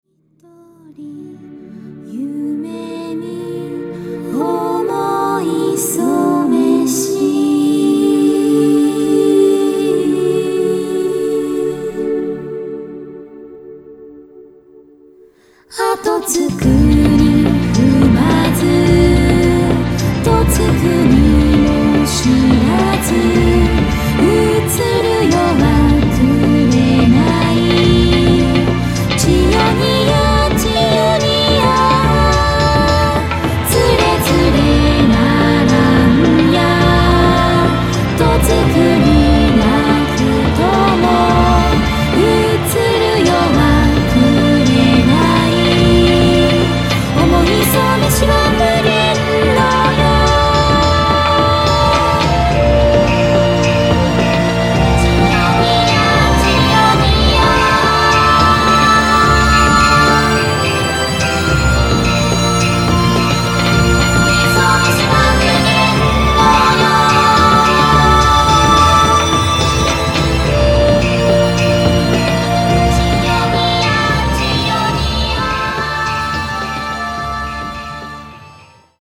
ボーカル